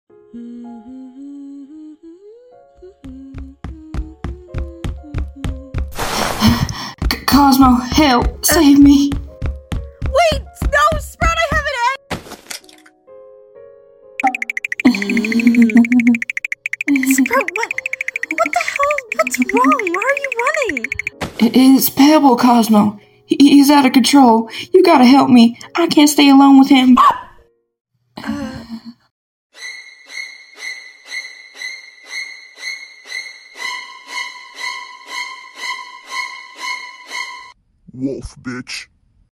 Dub
Amazing editing/Sound effects
Sprout voiced by me!